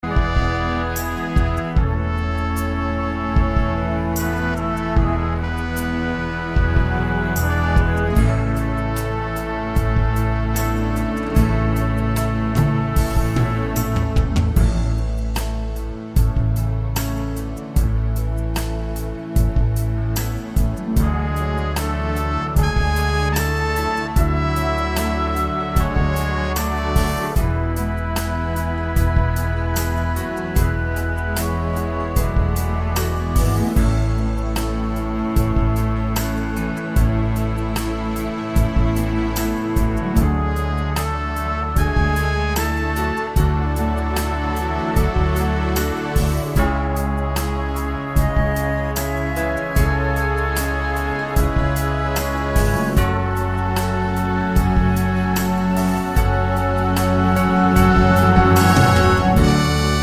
no Backing Vocals Crooners 4:21 Buy £1.50